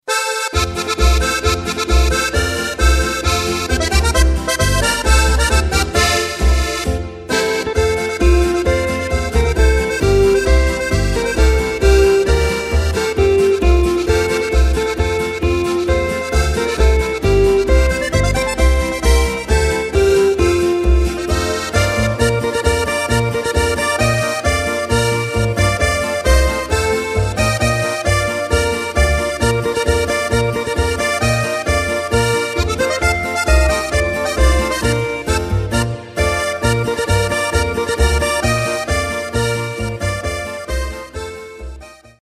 Takt:          4/4
Tempo:         133.00
Tonart:            F
Flotte Polka zum Prosit aus dem Jahr 2008!